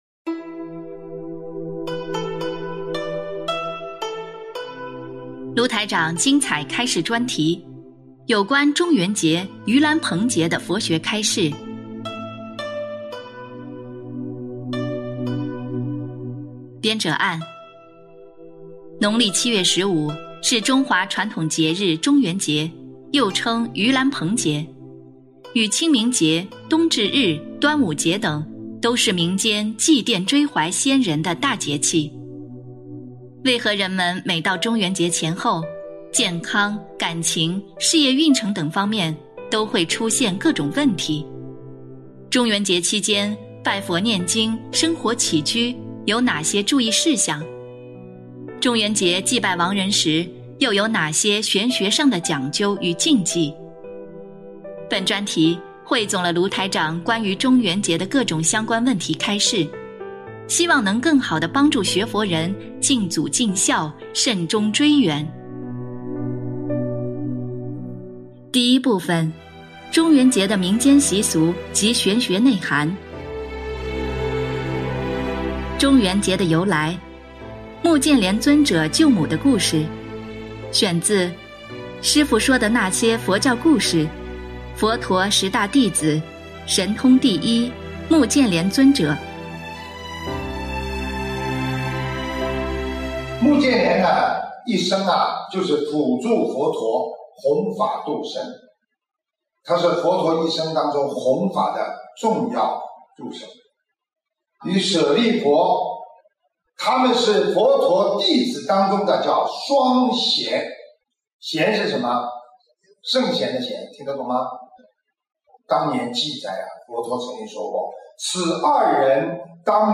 有关中元节（盂兰盆节）的佛学开示（一）